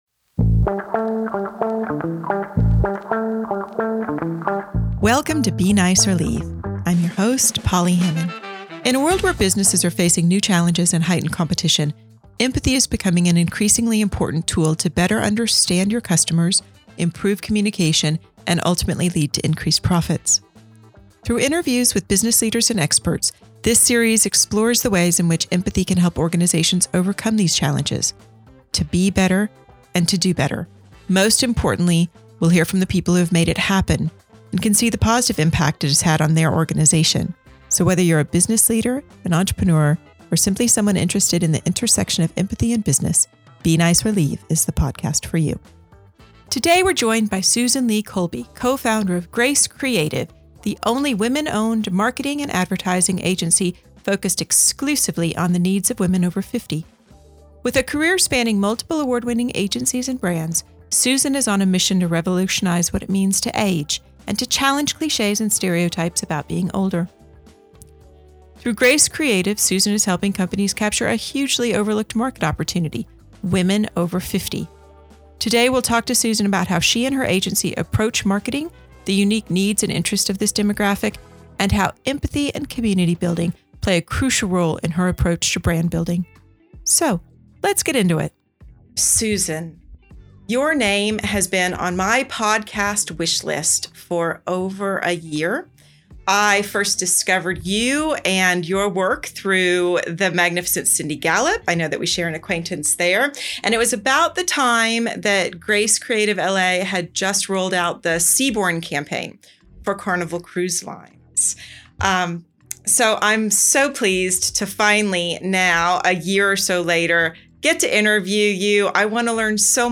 Welcome to Be Nice or Leave, a marketing podcast for anyone looking to unlock the power of empathy to drive success in their business.